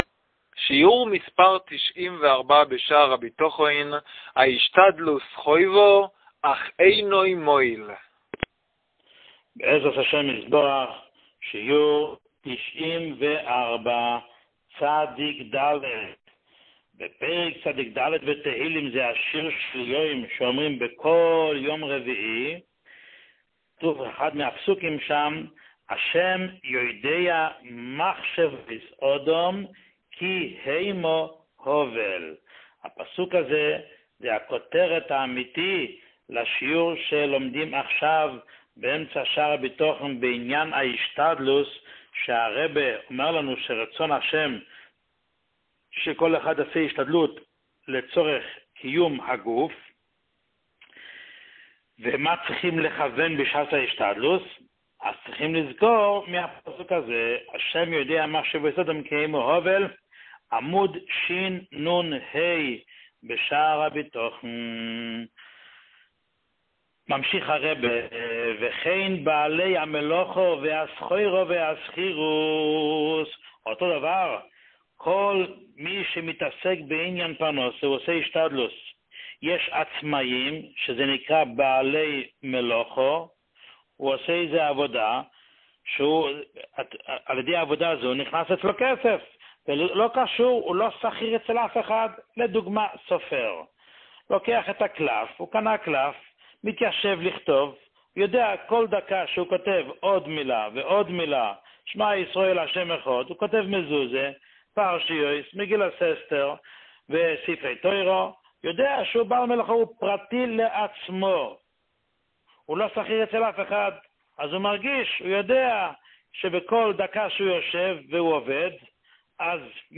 שיעור 94